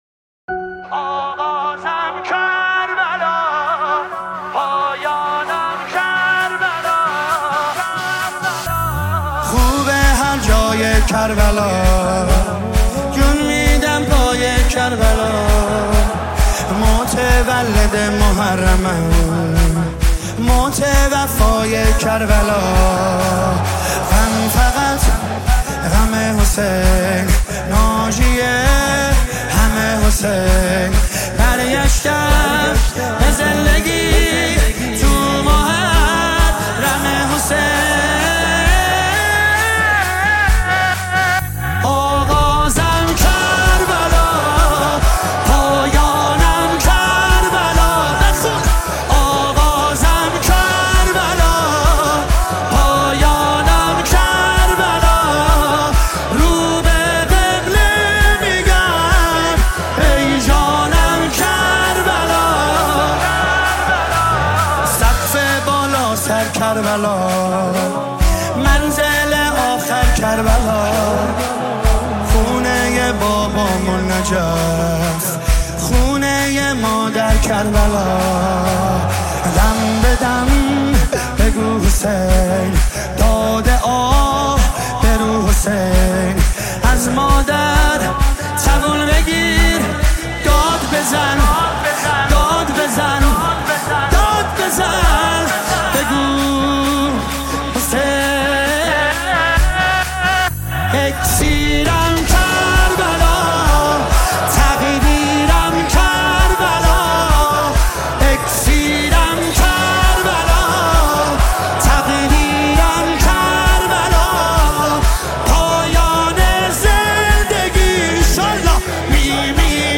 مداحی استودیویی
با متن شعر نوحه